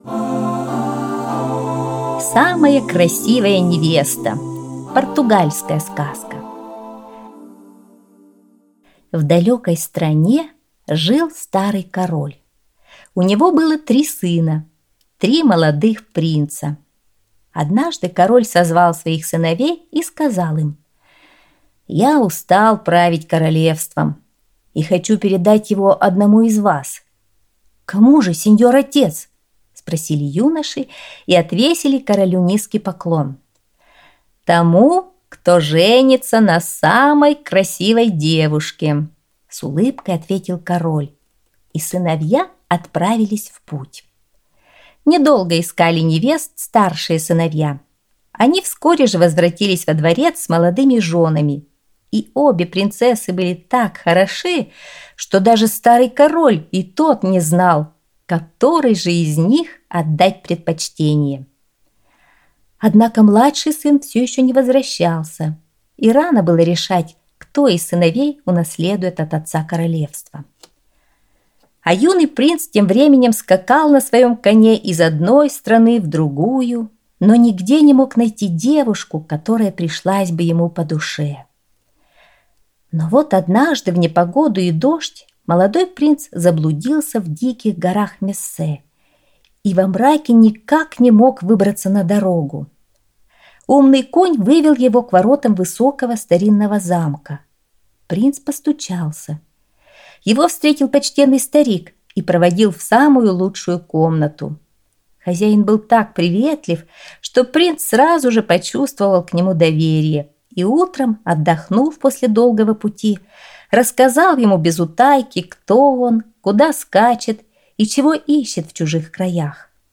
Аудиосказка «Самая красивая невеста»